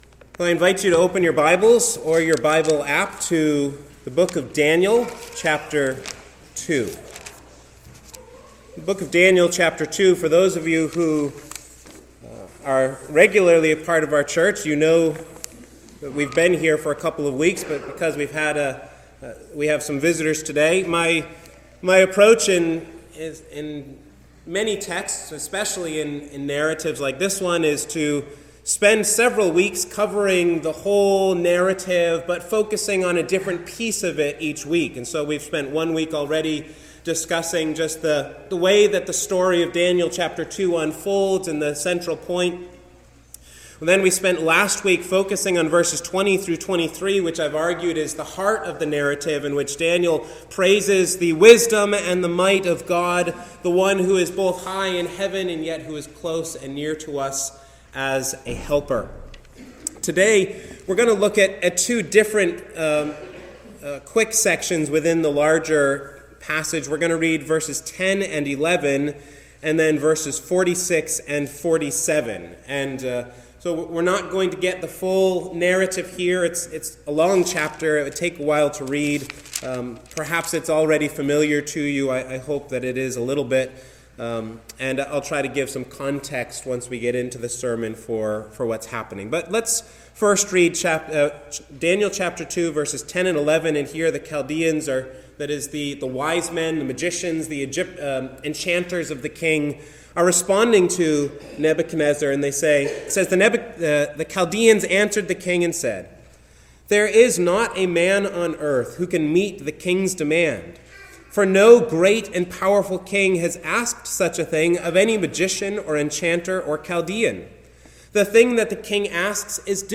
Truth and Error In Unbelievers | SermonAudio Broadcaster is Live View the Live Stream Share this sermon Disabled by adblocker Copy URL Copied!